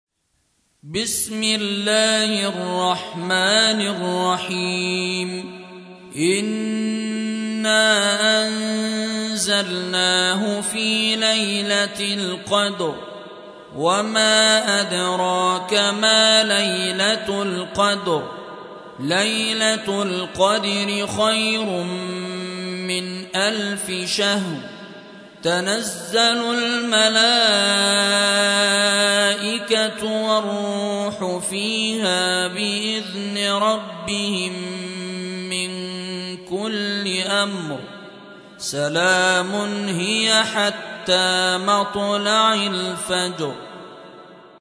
97. سورة القدر / القارئ